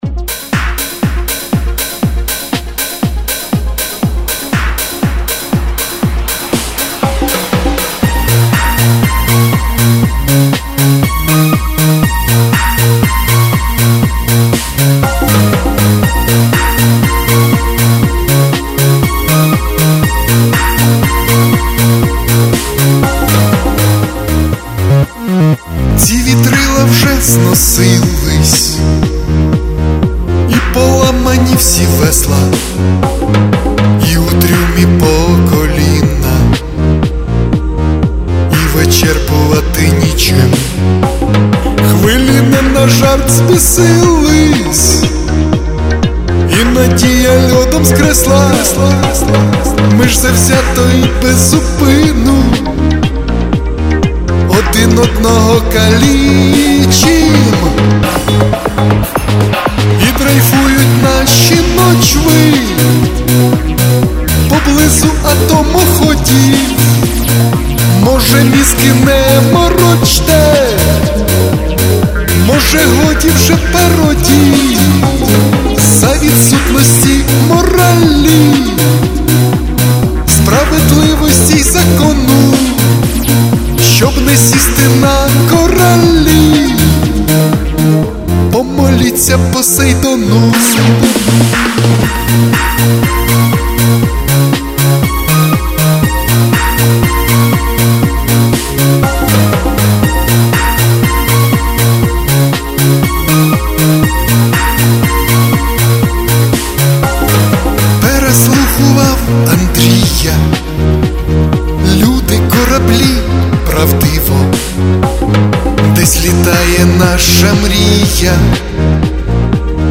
Ці вітрила (пісня і послання на відео)
Рубрика: Поезія, Авторська пісня